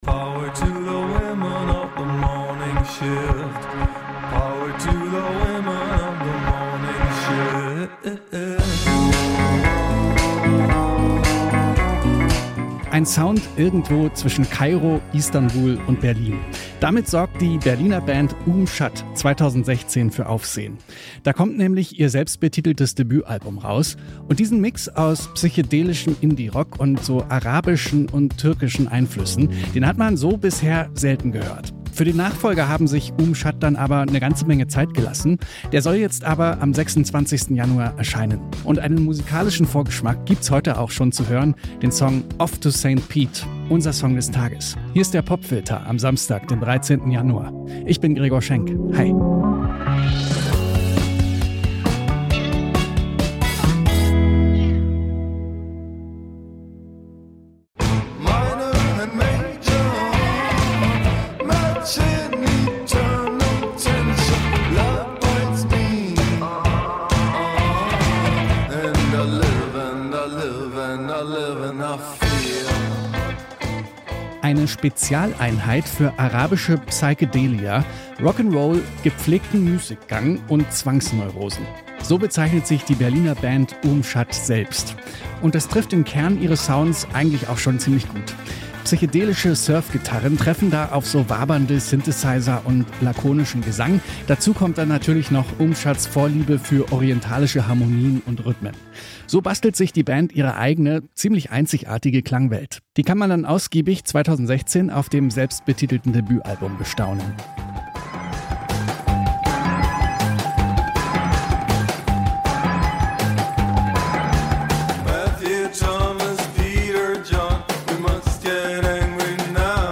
Psychedelischer Indie-Rock mit arabischen und türkischen Einflüssen – mit diesem Sound sorgt die Berliner Band Oum Shatt 2016 für Aufsehen. Acht Jahre nach dem Debütalbum erscheint demnächst der Nachfolger.